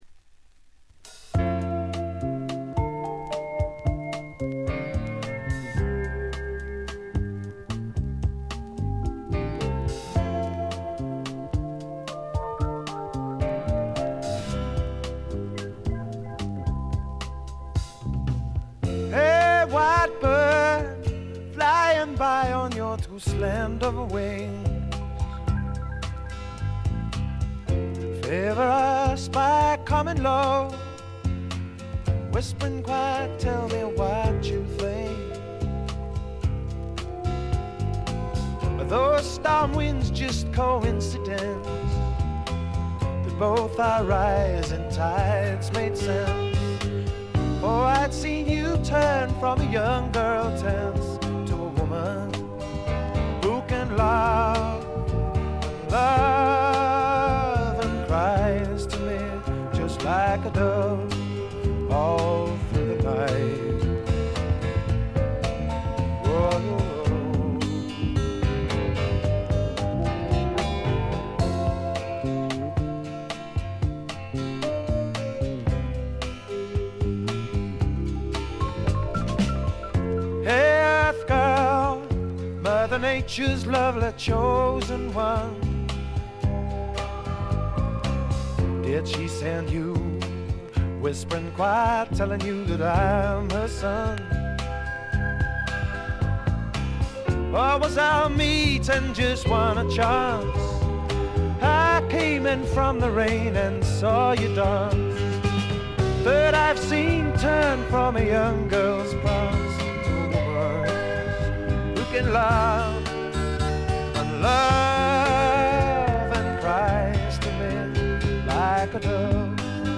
バックグラウンドノイズに軽微なチリプチ。
メランコリックで屈折した英国的翳りが底を流れているところが本作の最大の魅力かな？
試聴曲は現品からの取り込み音源です。